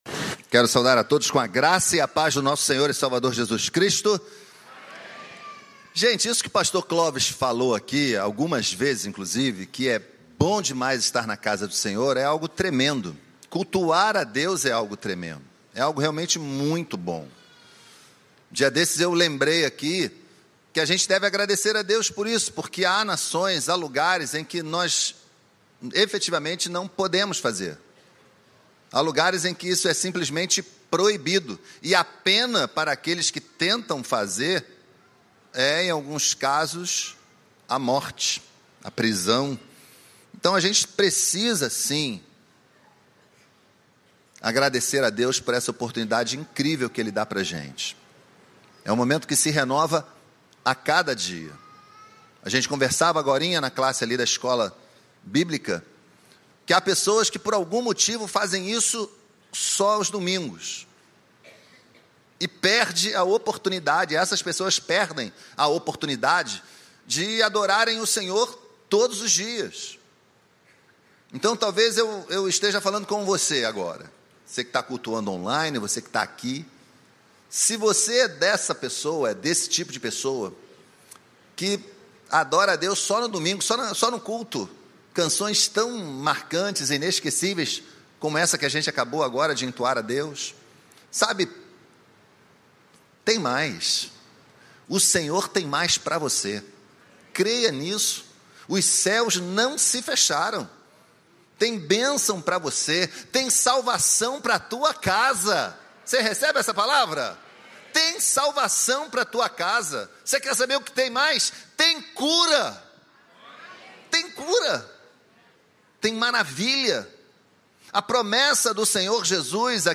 Mensagem
na Igreja Batista do Recreio.